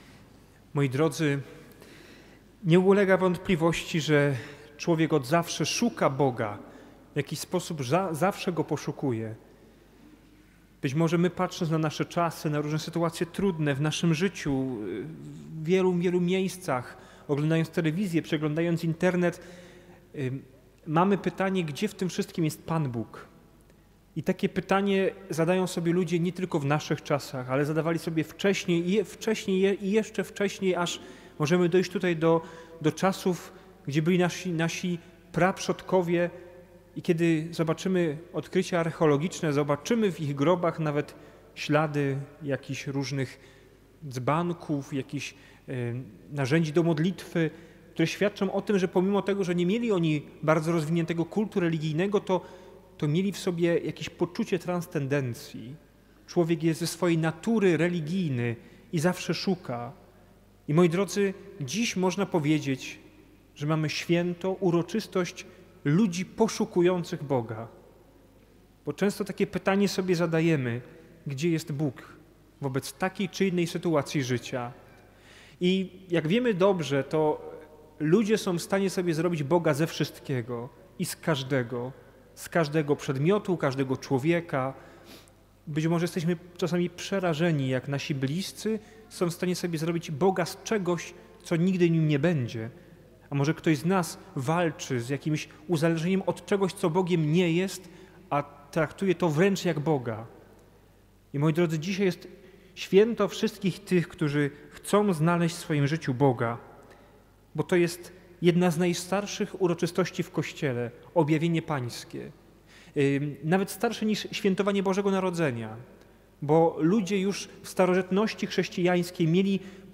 Całej homilii wygłoszonej w trakcie tej Mszy Świętej możecie wysłuchać poniżej.